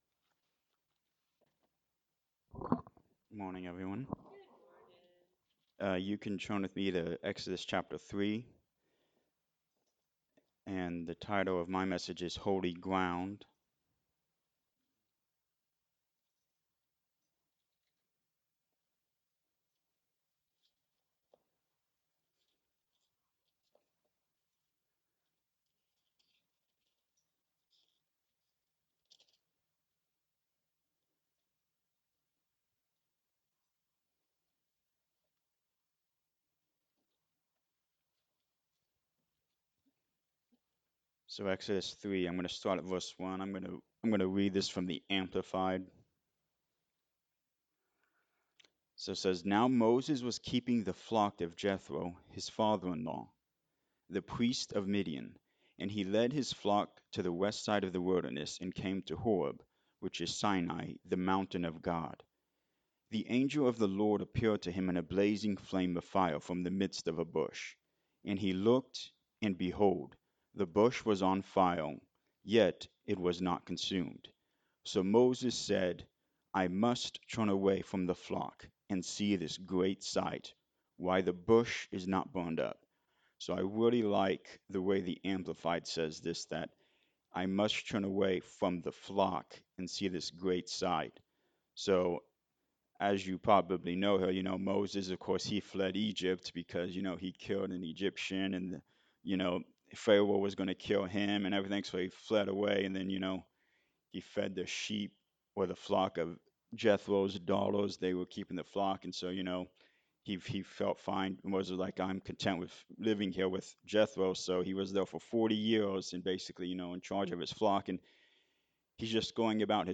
Exodus 3:1-3 Service Type: Sunday Morning Service Moses had a unique experience when he saw the burning bush.